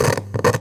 radio_tv_electronic_static_03.wav